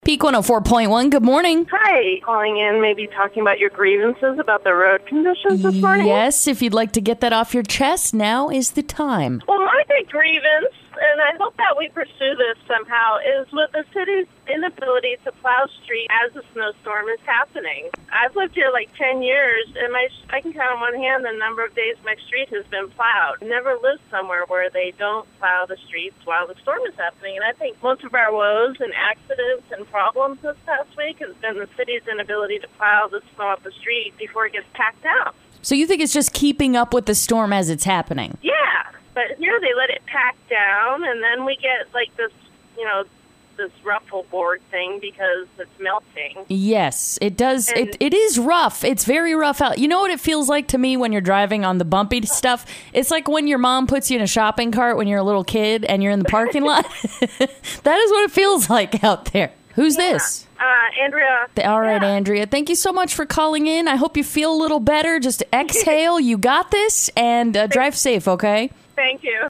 Friends of the show chime in on road conditions in Central Oregon this Winter. The RAGE is so real right now.